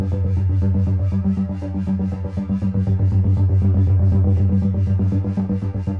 Index of /musicradar/dystopian-drone-samples/Tempo Loops/120bpm
DD_TempoDroneE_120-G.wav